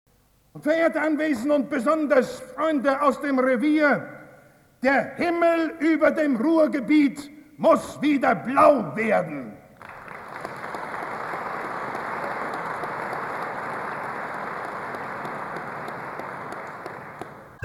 Inhalt: Das Regierungsprogramm der SPD. Mitschnitt des außerordentlichen Kongresses der SPD in Bonn (1961).